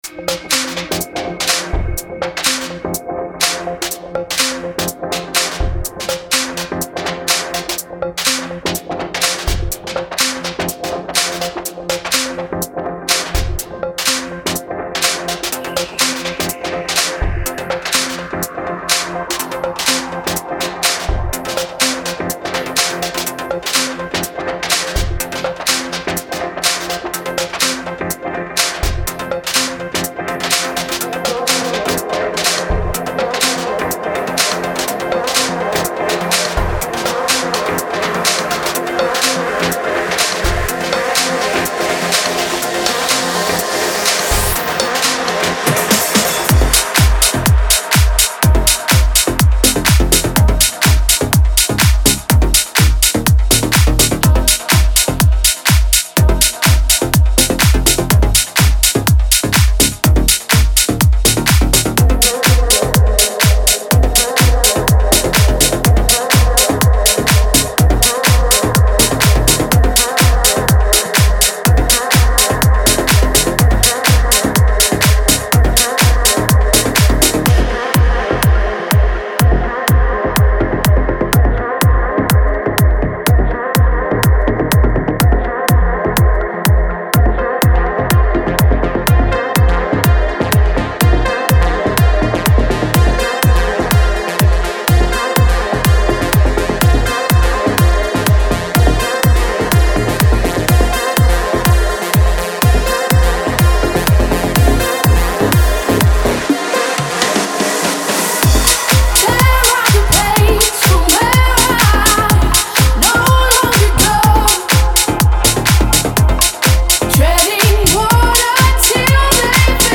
это энергичный трек в жанре электро-хаус